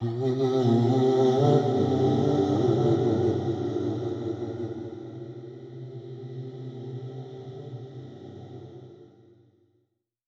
Index of /99Sounds Music Loops/Vocals/Melodies